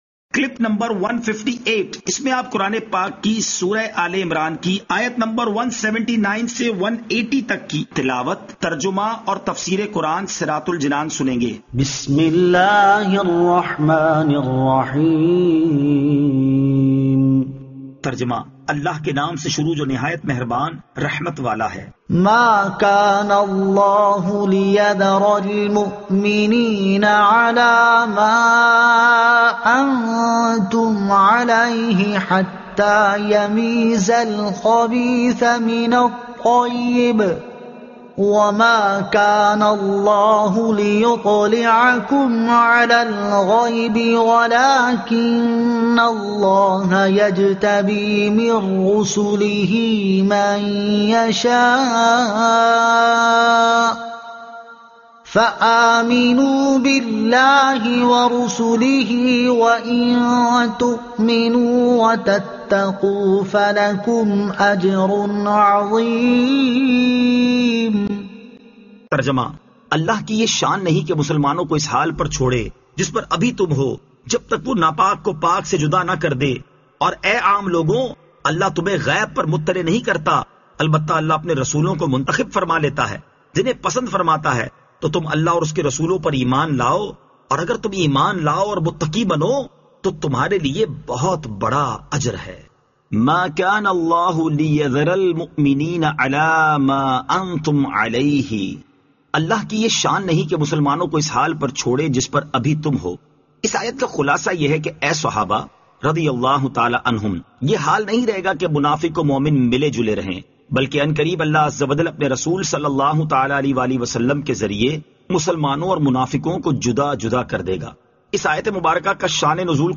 Surah Aal-e-Imran Ayat 179 To 180 Tilawat , Tarjuma , Tafseer